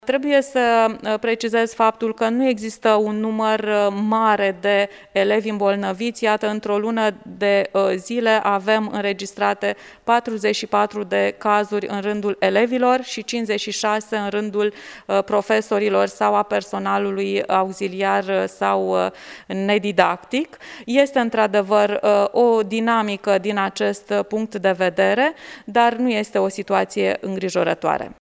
44 de elevi sunt infectaţi cu virusul Sars CoV2, în judeţul Iaşi şi la aceştia se adaugă 56 de profesori COVID pozitiv, Inspectorul general al Inspectoratului Şcolar Judeţean Iaşi, Genoveva Farcaş: